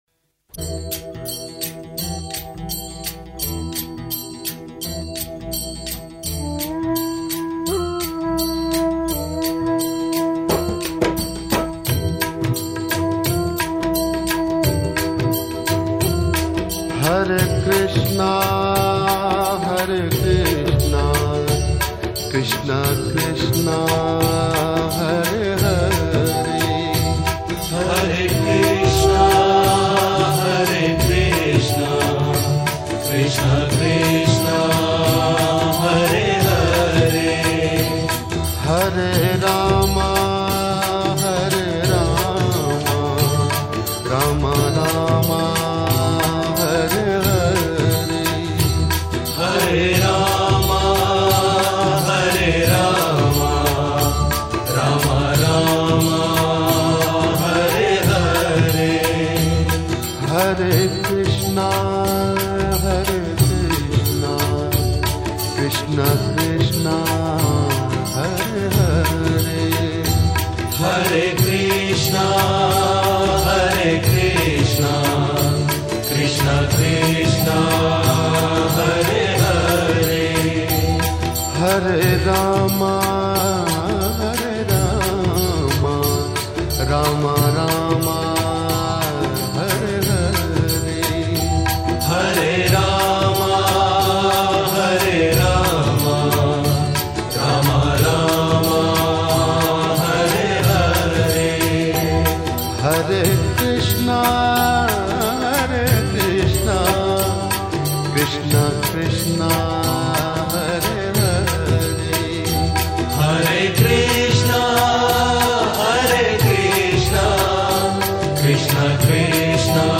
Devotional Songs
Krishna Bhajans